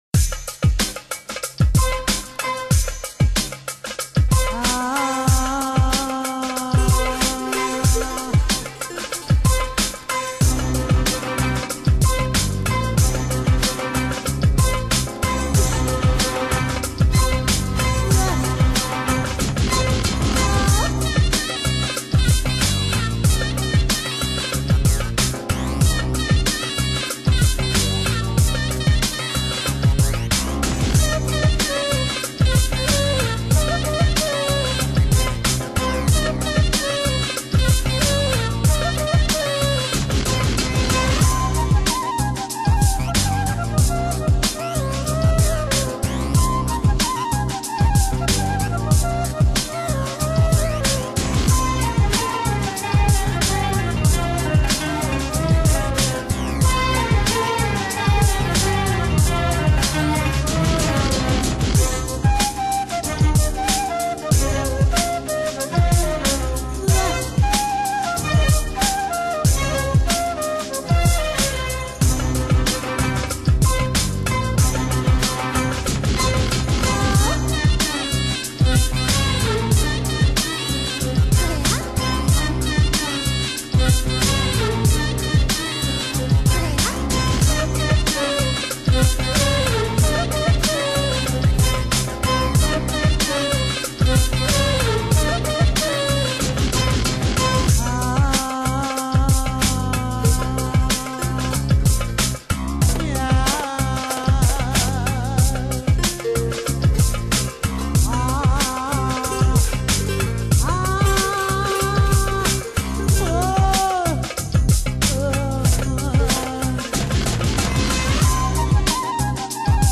Genre: Lounge, Chillout, Downtempo